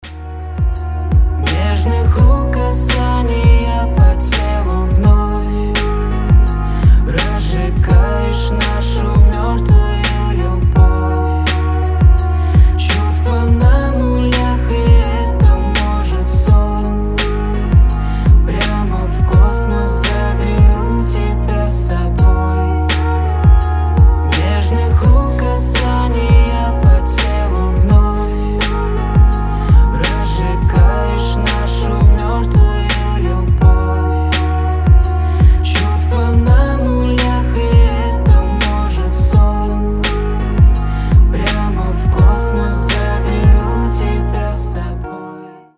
• Качество: 320, Stereo
русский рэп
дуэт
нежные